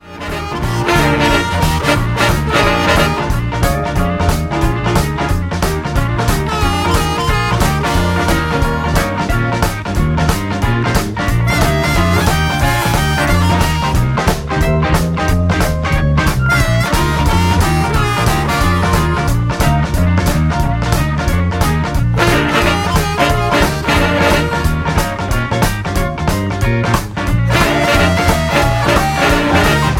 Bb
Backing track Karaoke
Pop, 1970s